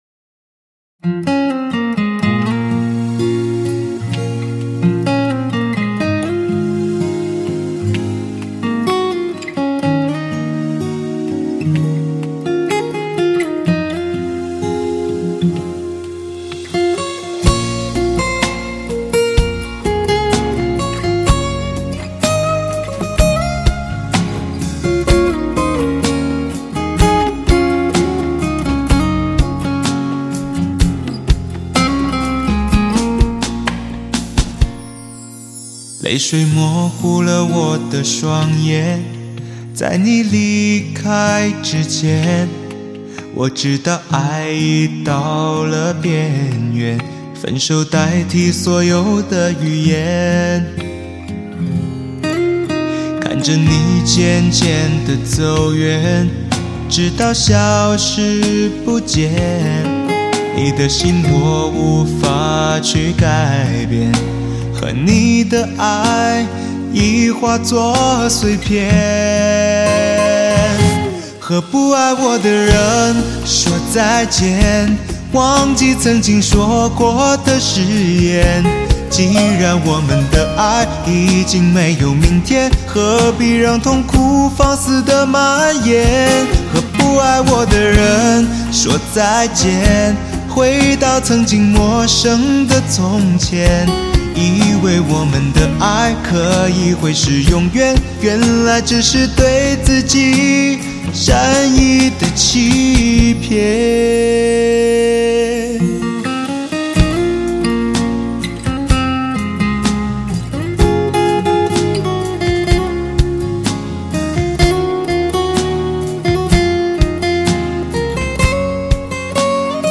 独一无二的磁性嗓音 赋予歌曲别样韵味，粒粒靓声散发男人四十的醇厚与淡然。
大师级幕后制作团队，极致发烧的音响效果成为极品的珍藏。